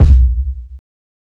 KICK_TIN_CAN.wav